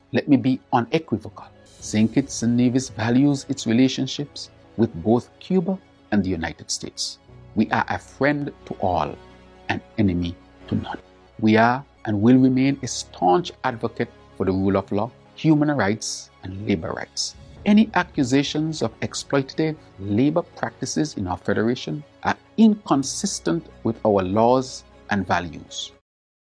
SKN’s Prime Minister, the Hon. Terrance Drew. His comments were made during a statement on Friday Mar. 14th.